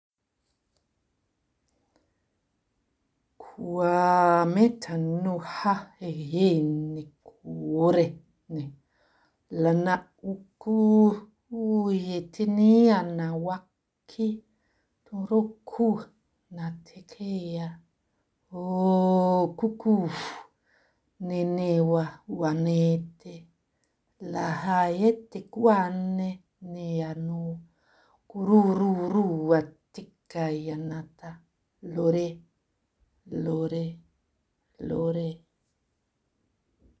Die Lichtsprache ist pure ENERGIE und FREQUENZ.
Es sind keine Worte oder Sätze, die wir mit unserem Verstand verstehen, weil wir sie gelernt haben.